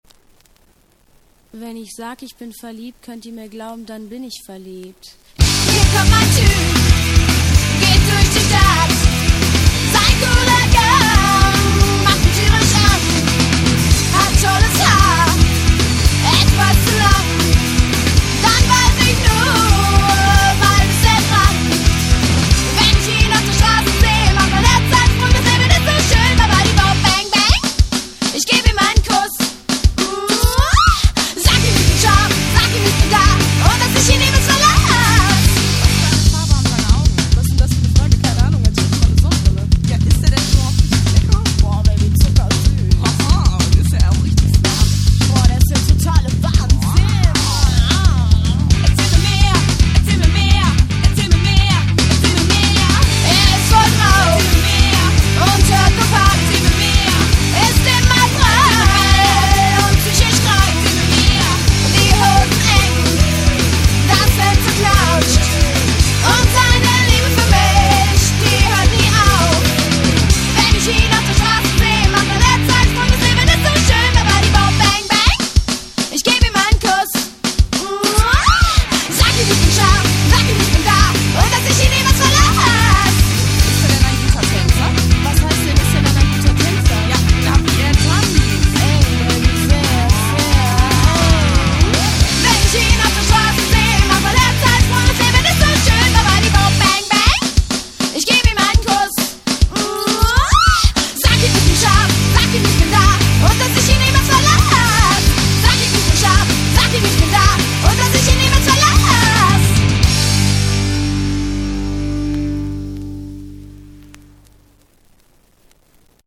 einzige coverversion auf deutsch ,
schöne lp ,gute band aus velbert